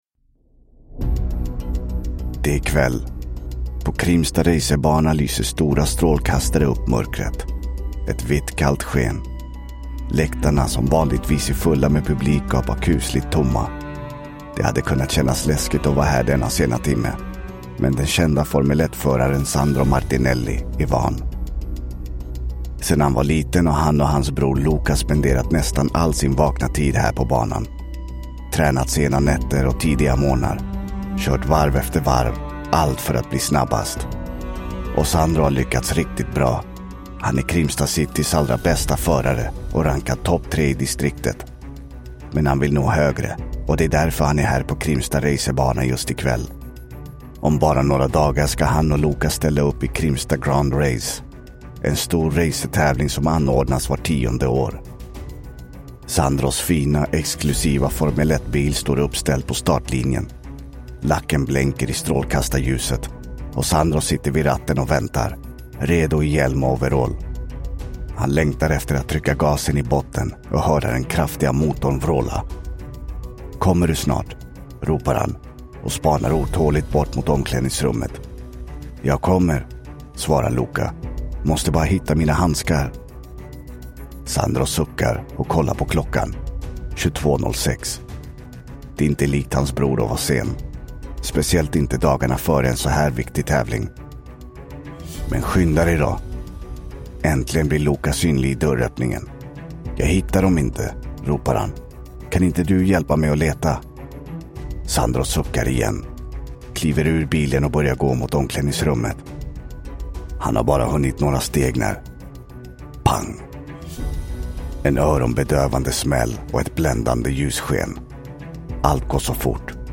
Den sprängda bilen – Ljudbok